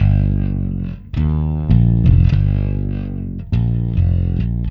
Ala Brzl 1 Bass-G.wav